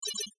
NOTIFICATION_Digital_03_mono.wav